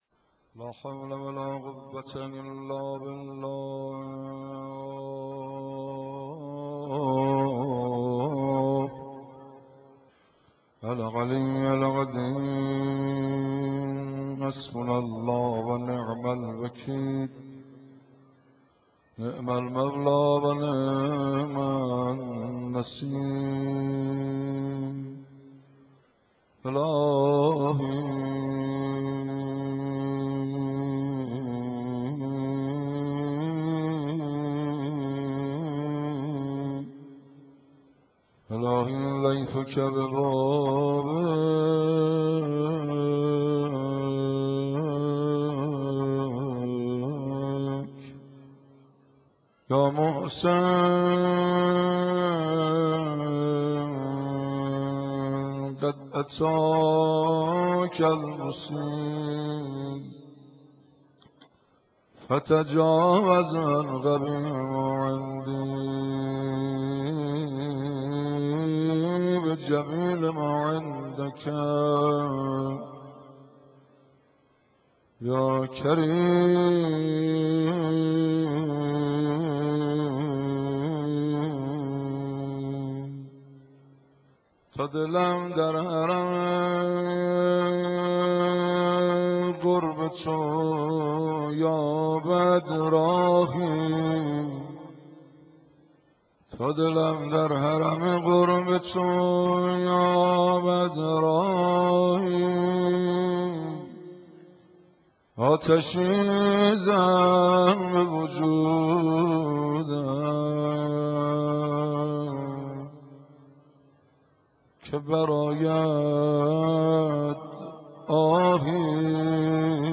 مناجات شعبانیه در حرم مطهر حضرت معصومه(س)